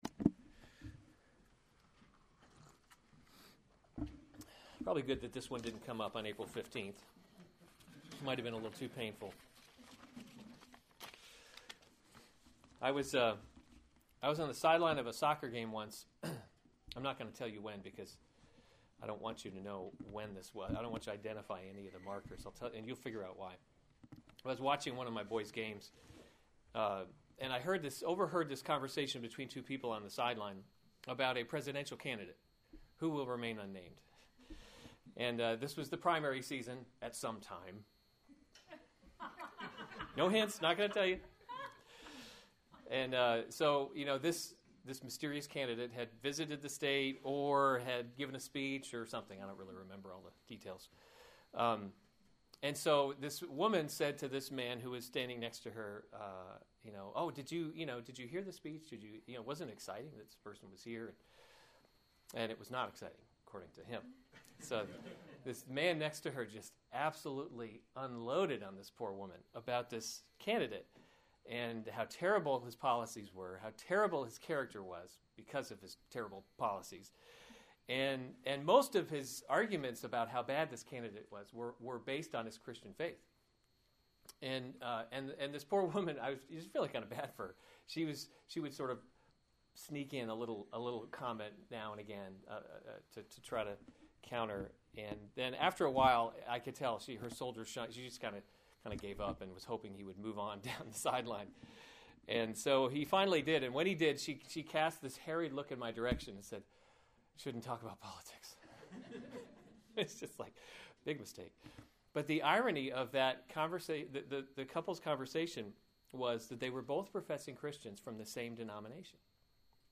March 14, 2015 Romans – God’s Glory in Salvation series Weekly Sunday Service Save/Download this sermon Romans 13:1-7 Other sermons from Romans Submission to the Authorities 13:1 Let every person be […]